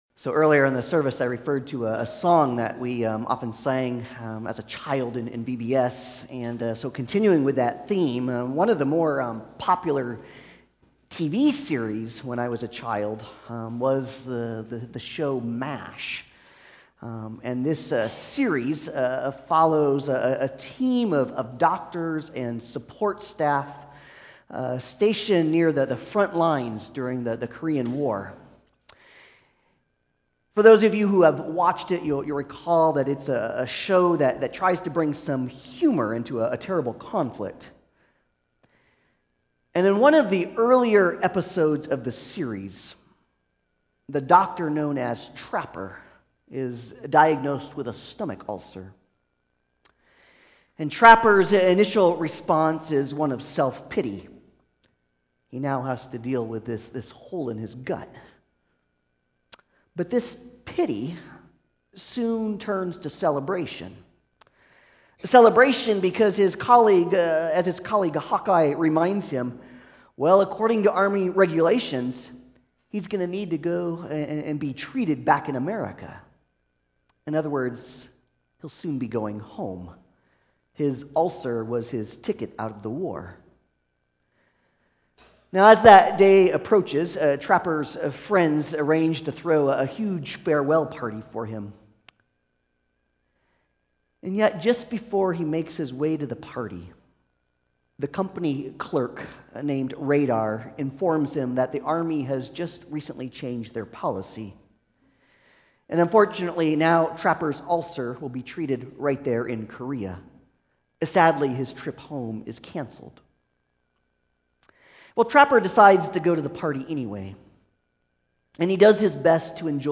Passage: Luke 19:28-40 Service Type: Sunday Service « The Raising of Lazarus from the Dead Why Are You Crying?